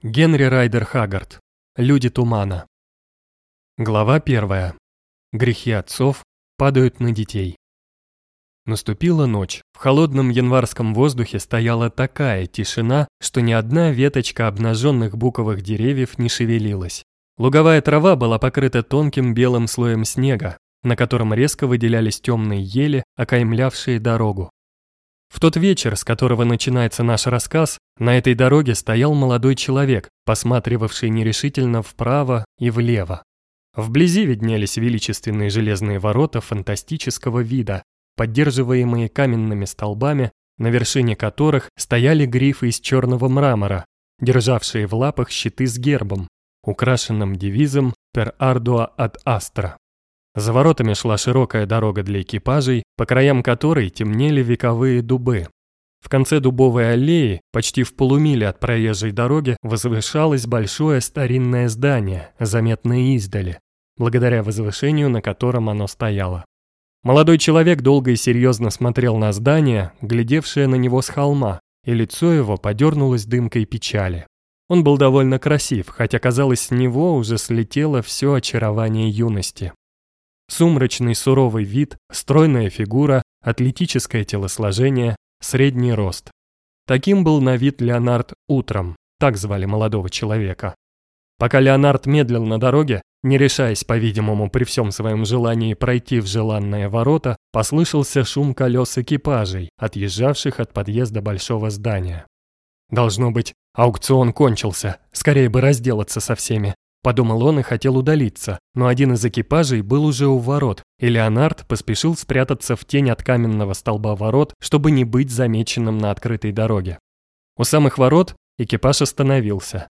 Аудиокнига Люди тумана | Библиотека аудиокниг